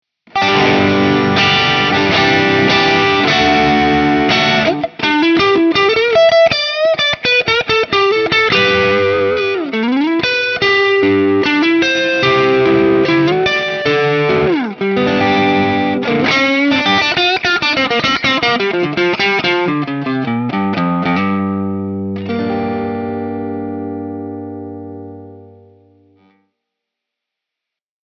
Tutte le clip audio sono state registrate con amplificatore Fender Deluxe e una cassa 2×12 con altoparlanti Celestion Creamback 75.
Clip 2 – Stratocaster Classic Gain, clean amp
Chitarra: Fender Stratocaster (pickup al ponte)
Over Drive: 5/10